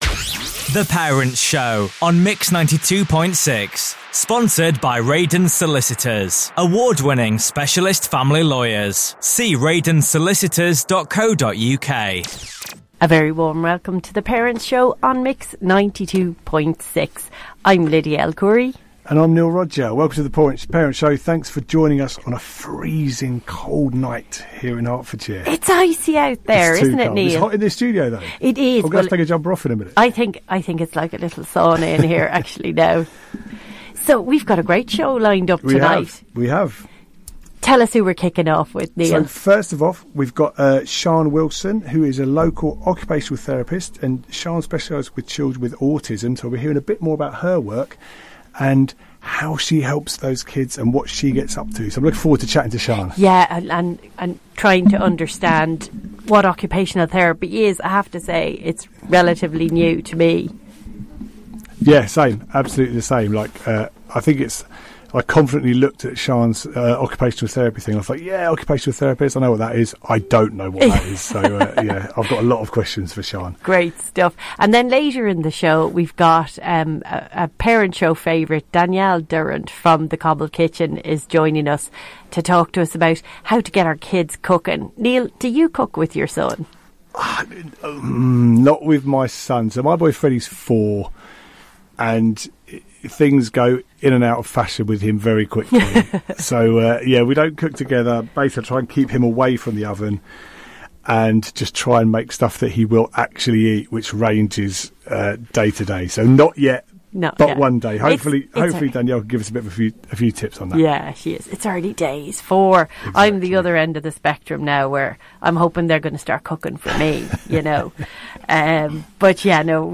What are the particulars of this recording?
This week, we’re joined in the studio